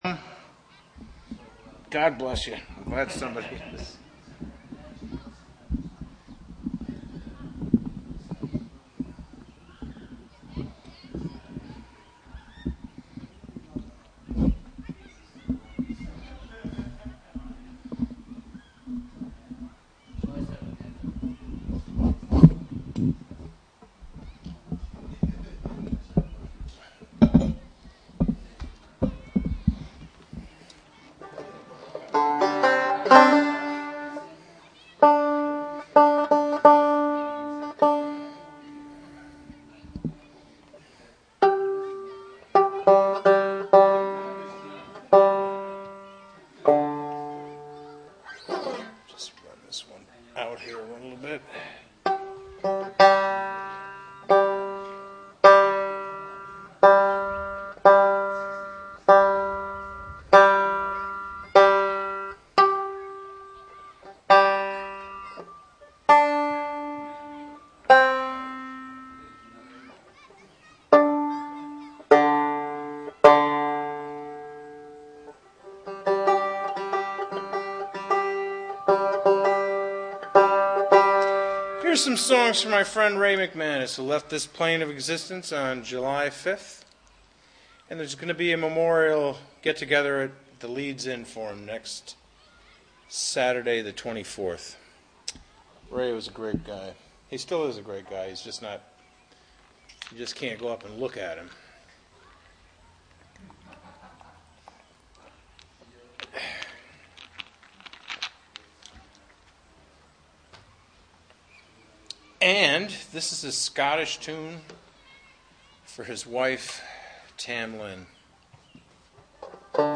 Cairo Open Mic at Cairo town park sponsored by Cairo Library and WGXC.